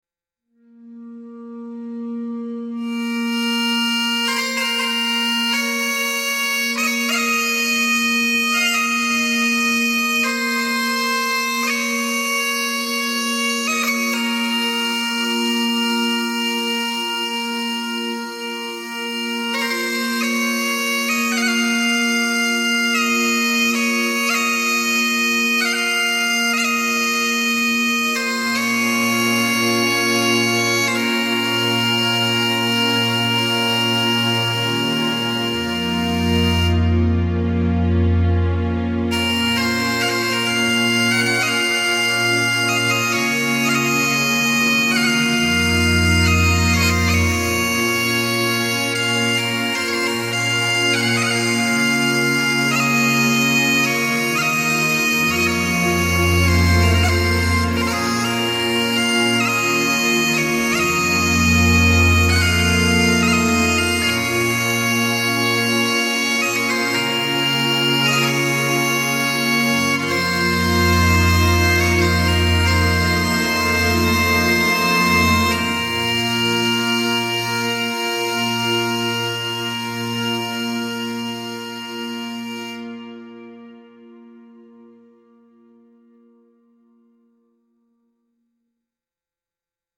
bagpipe lament with soft orchestral accompaniment and respectful tone